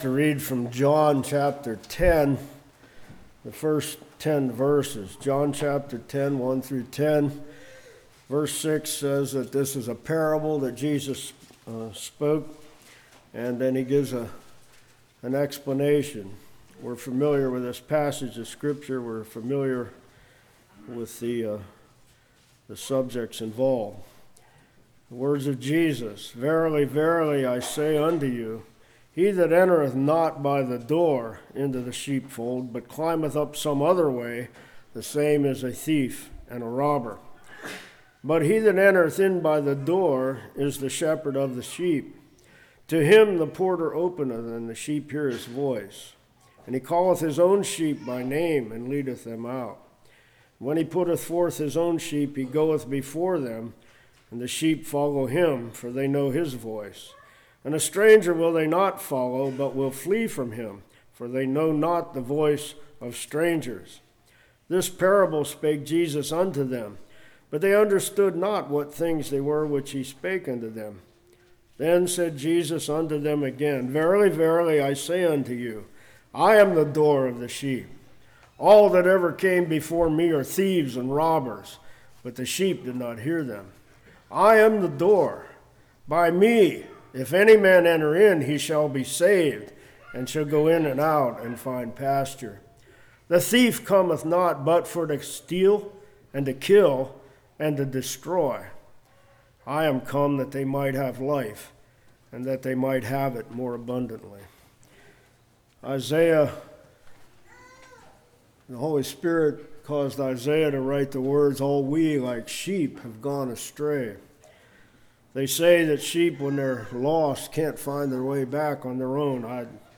John 10:1-10 Service Type: Morning Fear and Anxiety Terrible Thoughts Renewing the Mind « Prevailing Prayer Eternal Security